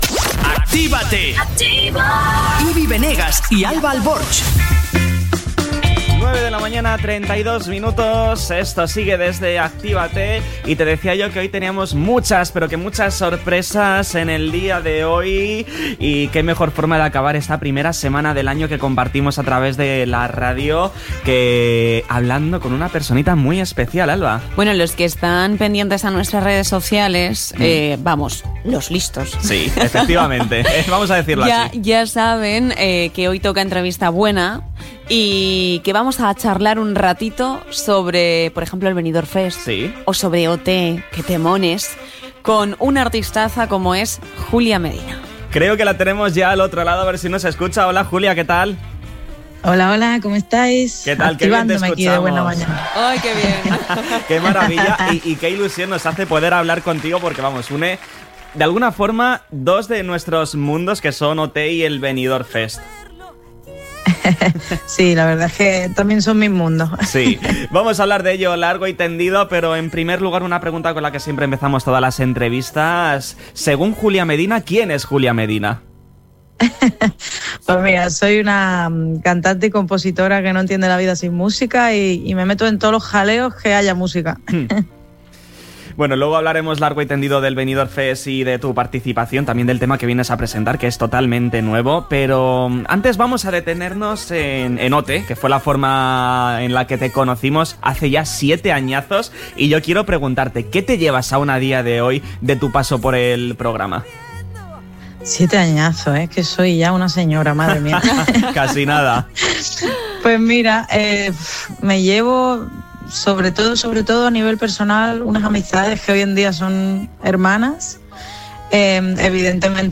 ENTREVISTA-JULIA-MEDINA.mp3